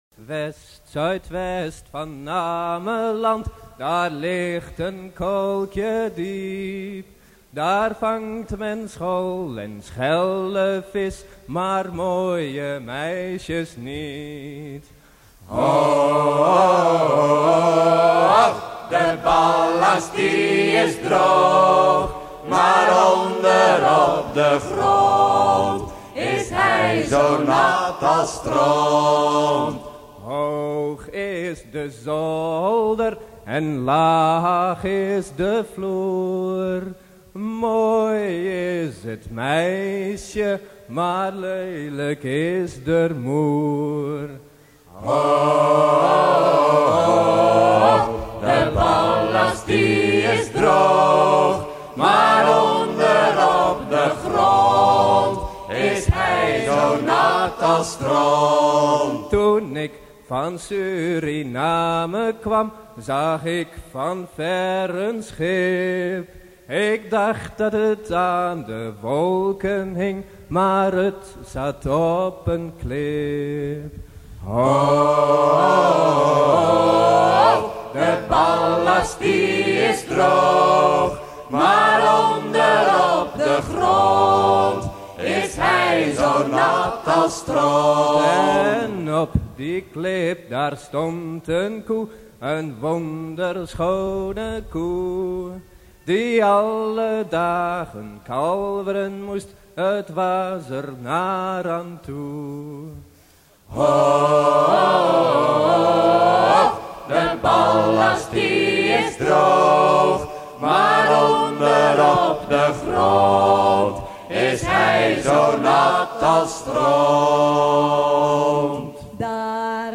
Chants de travail de Terschelling